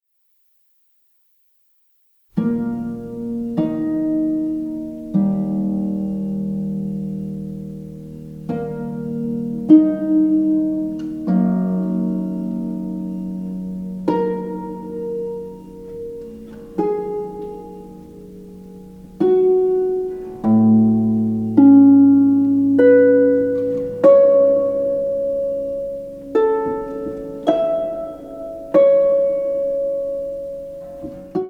Harp
Flute
Viola Released